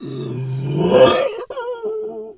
bear_pain_hurt_groan_05.wav